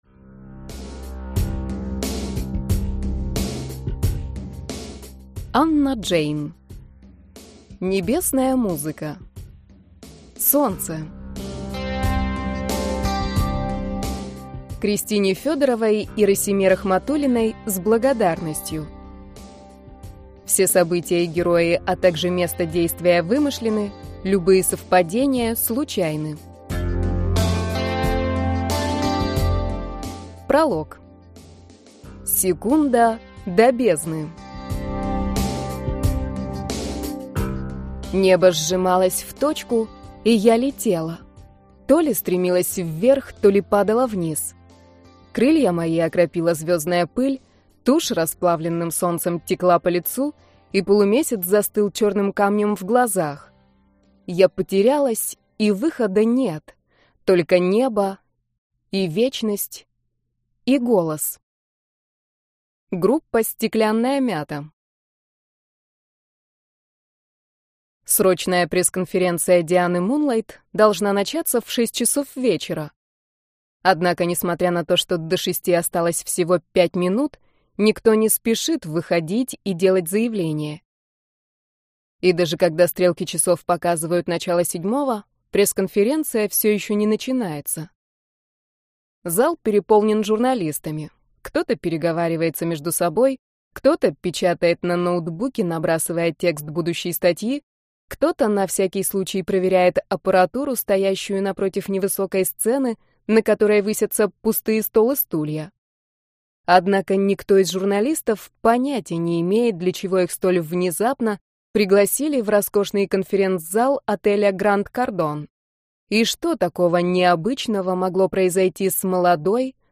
Аудиокнига Небесная музыка. Солнце | Библиотека аудиокниг